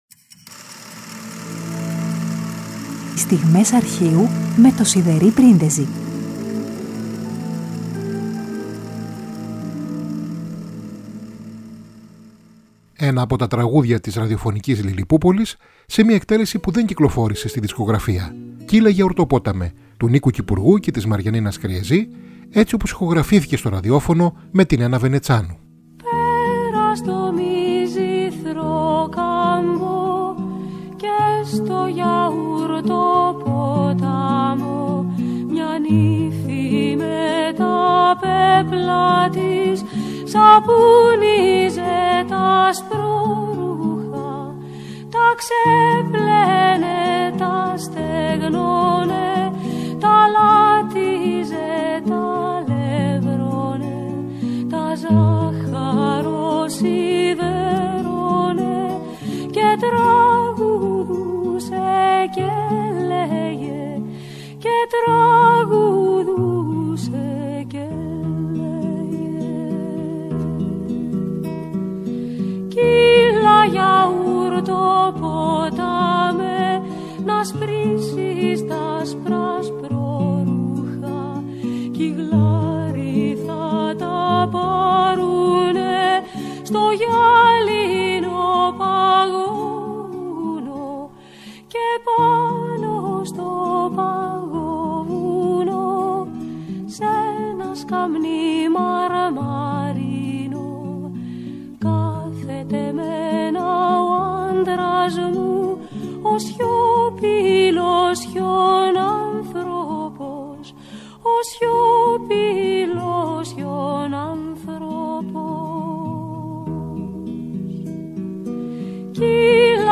τραγούδι
ραδιοφωνική ηχογράφηση που δεν κυκλοφόρησε στη δισκογραφία